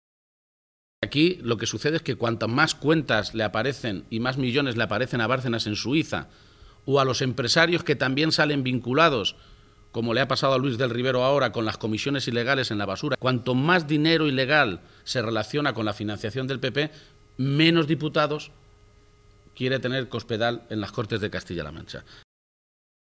Emiliano García-Page durante el desayuno informativo celebrado en Cuenca